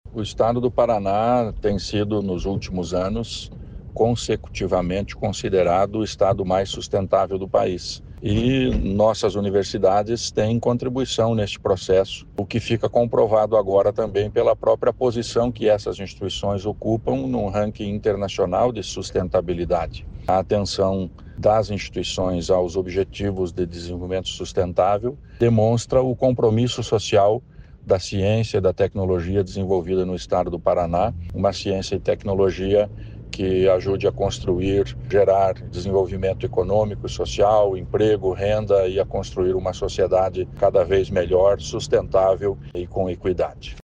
Sonora do secretário da Ciência, Tecnologia e Ensino Superior, Aldo Bona, sobre o ranking global que aponta universidades estaduais como referência em ODS